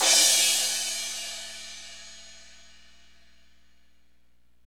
Index of /90_sSampleCDs/Northstar - Drumscapes Roland/CYM_Cymbals 1/CYM_F_S Cymbalsx